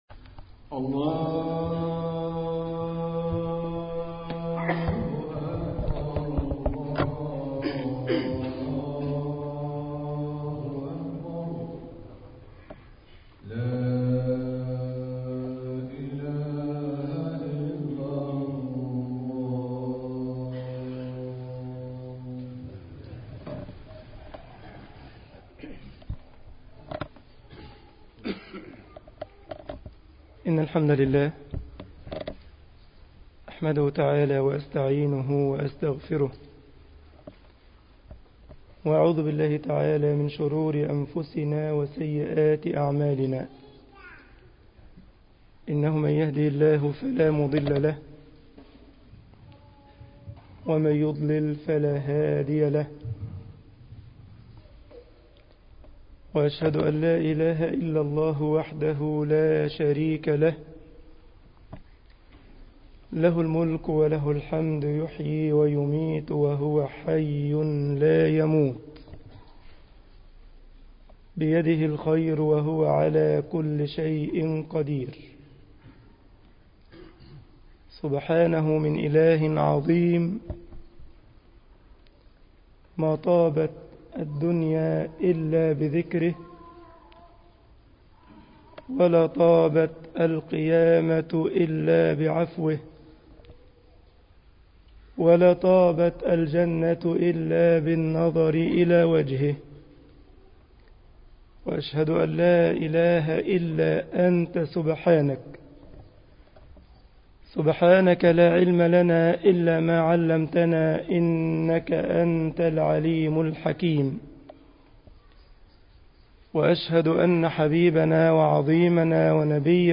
مسجد الجمعية الاسلامية بالسارلند خطبة الجمعة